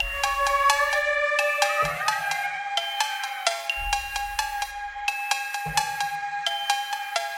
劲爆的音符808牛铃的旋律
描述：有气氛的高通量钟声，让我看看你做了什么
标签： 130 bpm Hip Hop Loops Bells Loops 1.24 MB wav Key : Unknown FL Studio
声道立体声